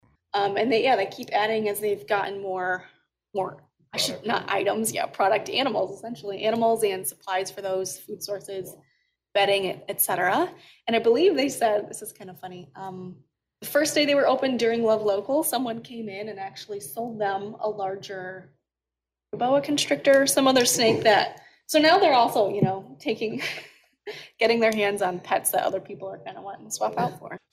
talked about the Jungle Pets store during this week’s Downtown Development Authority meeting.